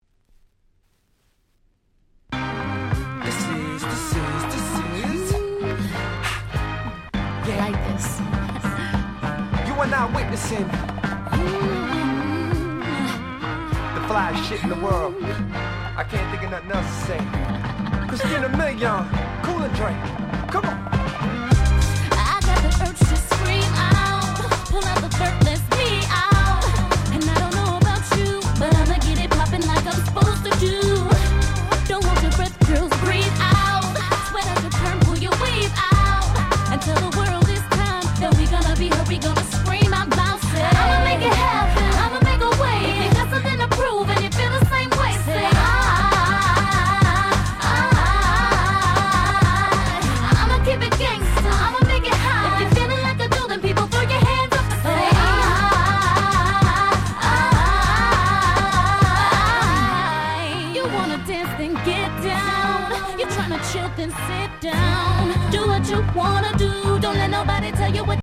06' Super Hit R&B !!